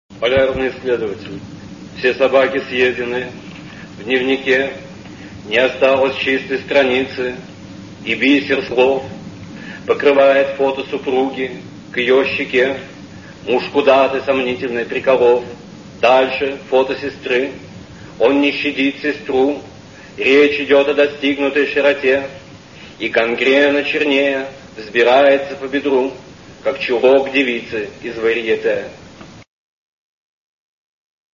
iosif-brodskij-chitaet-avtor-polyarnyj-issledovatel